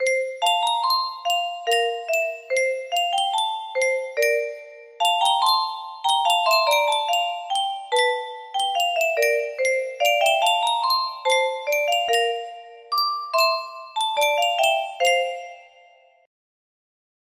Yunsheng Music Box - Unknown Tune 2721 music box melody
Full range 60